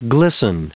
Prononciation du mot glisten en anglais (fichier audio)
Prononciation du mot : glisten